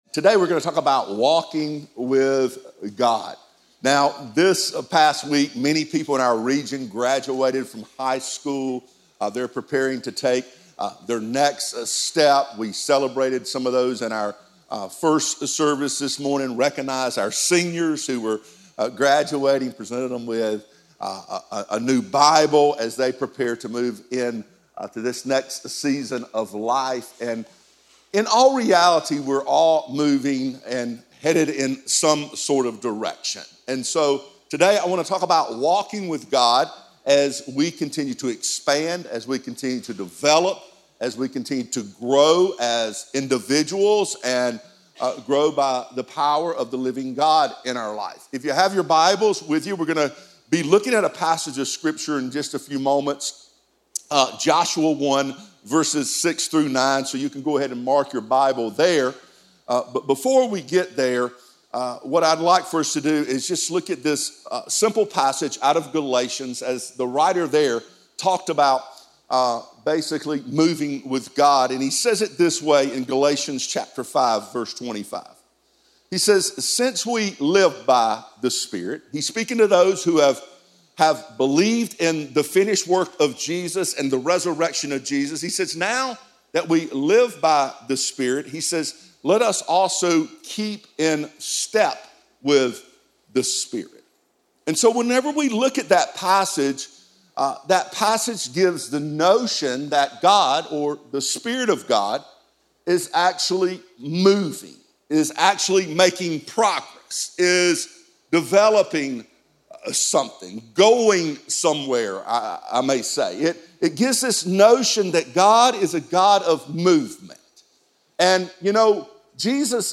a dynamic, high-energy speaker